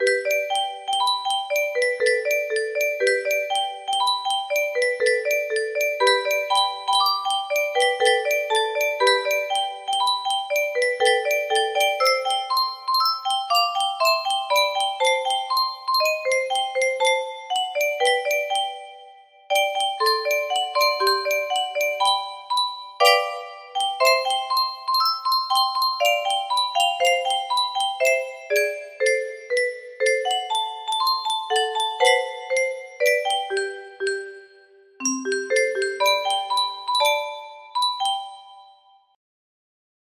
Widmung - Schumann/Liszt music box melody